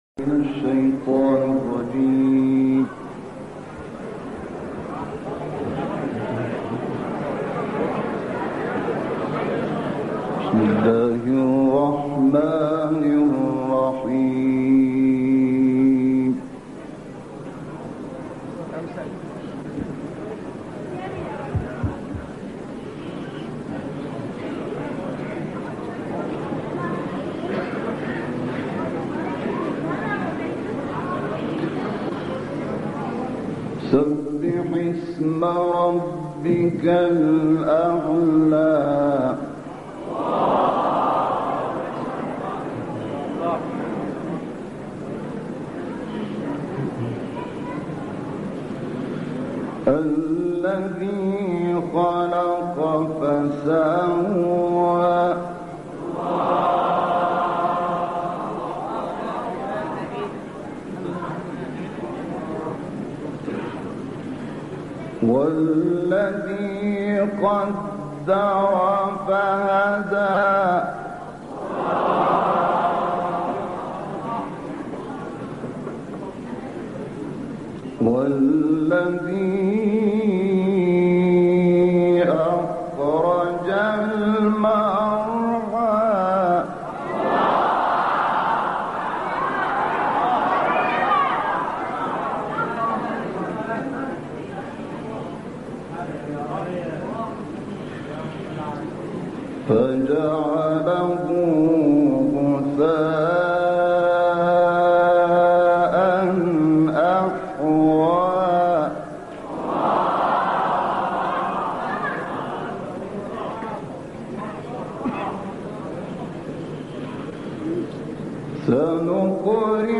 صوت | تلاوت شحات محمد انور از سوره «اعلی» و «غاشیه»
تلاوت شنیدنی از استاد شحات محمد انور از سوره «اعلی» و «غاشیه» تقدیم مخاطبان ایکنا می‌شود.
تلاوت سوره اعلی